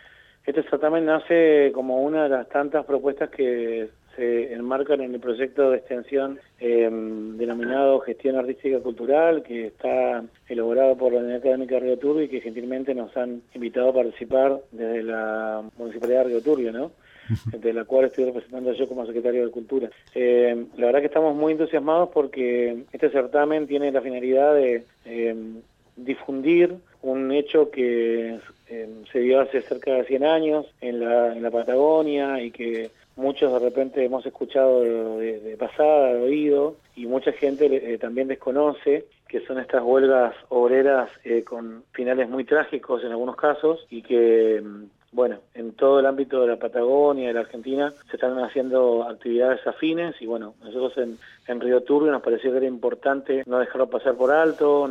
El Prof. Ricardo Díaz, Secretario de Cultura, Educación y Turismo de la Municipalidad de Rio Turbio, informó sobre las inscripciones para participar del certamen “La Patagonia Rebelde” en conmemoración el Centenario de las Huelgas Obreras Patagónicas de 1921.